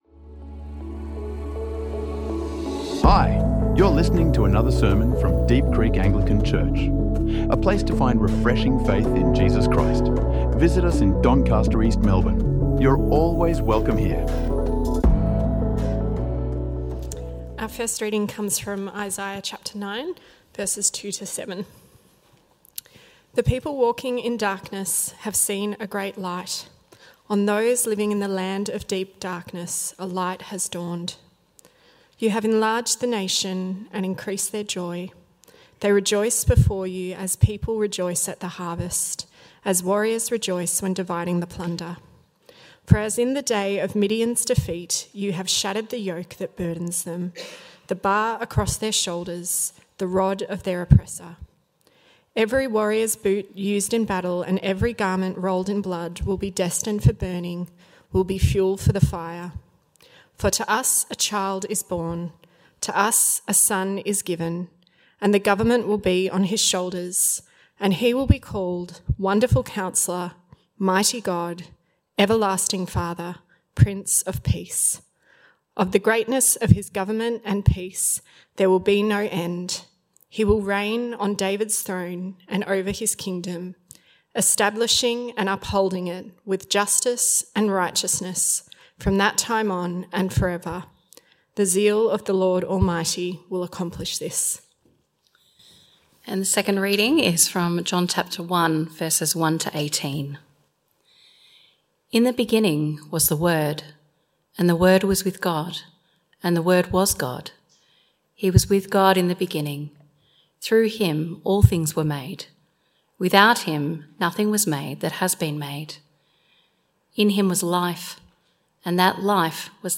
Beholding the Glory, A Christmas Eve Sermon | Deep Creek Anglican Church
Discover the true joy of Christmas in this sermon exploring how the ancient promise of light meets our modern darkness.